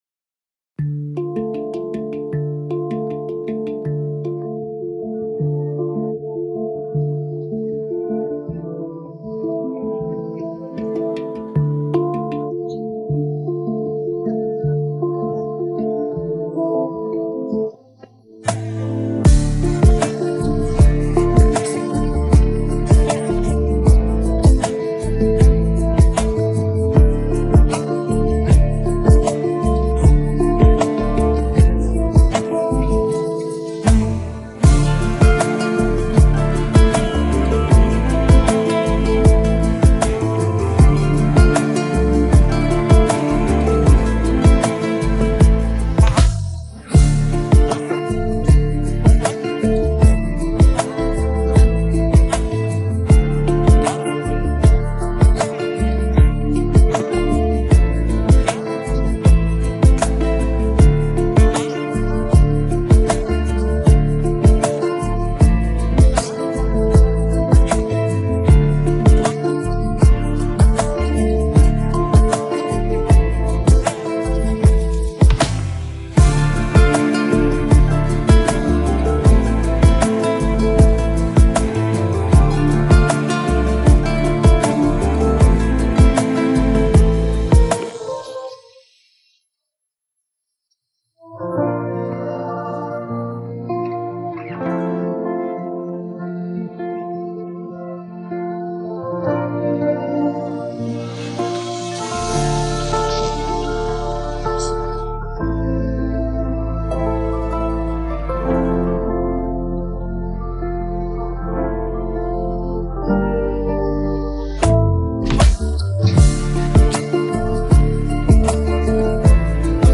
پخش نسخه بی‌کلام
download-cloud دانلود نسخه بی کلام (KARAOKE)